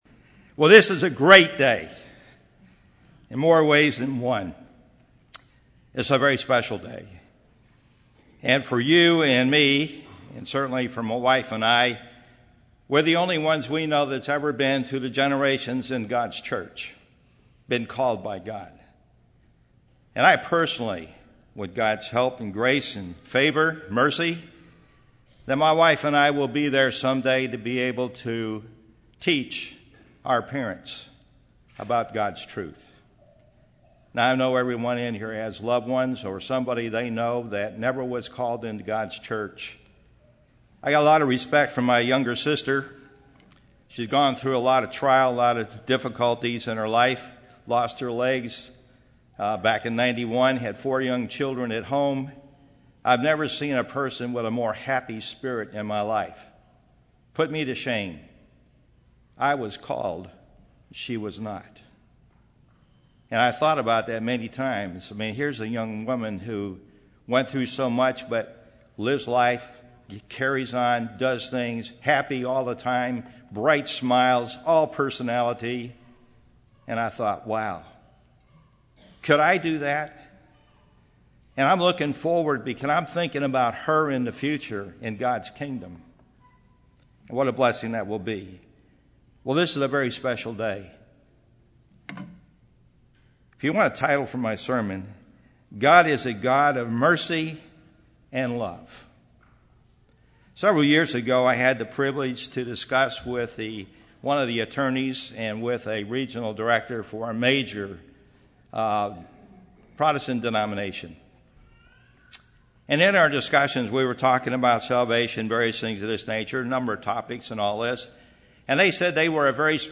This sermon was given at the New Braunfels, Texas 2013 Feast site.